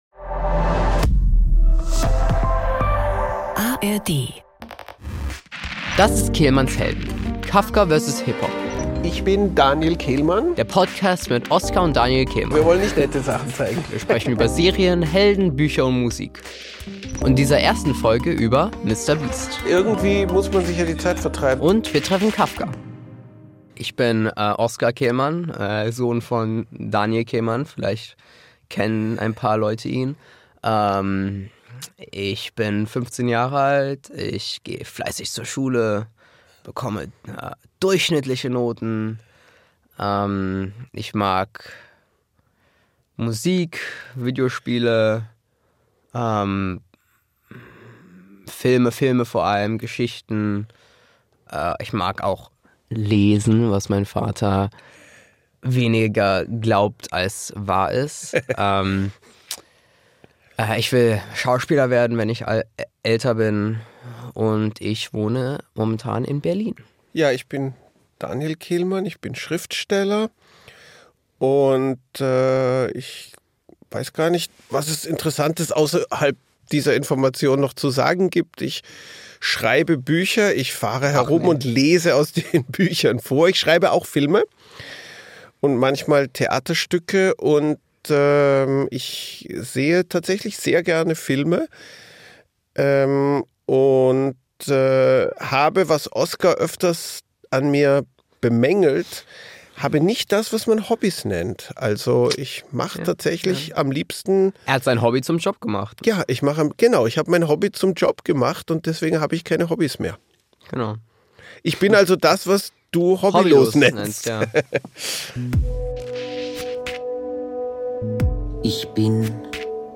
In dieser ersten Folge geht es damit in einen lauten und sehr schrillen Supermarkt.